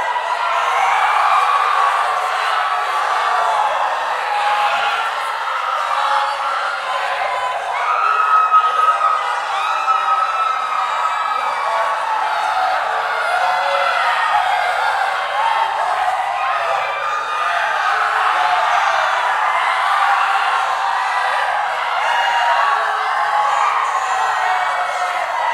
teenageCrowdPanicLoop.ogg